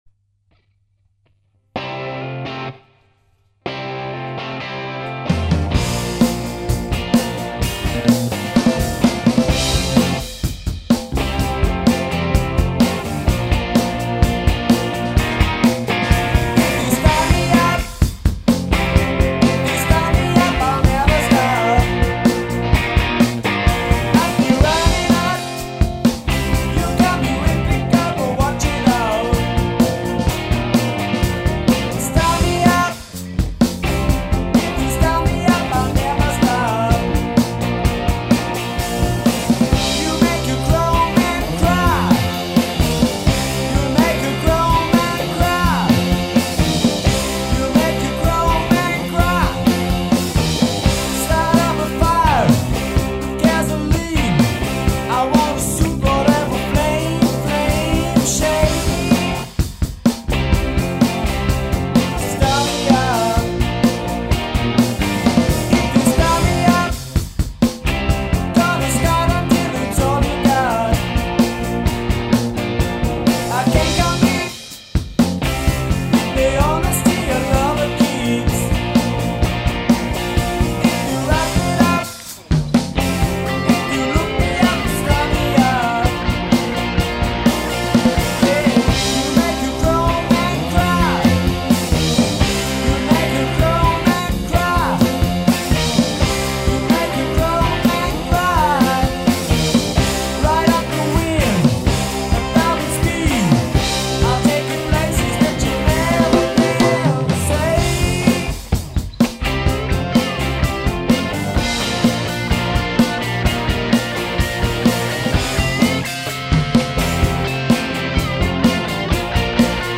Lediglich am Mastereffekt lässt sich noch schrauben.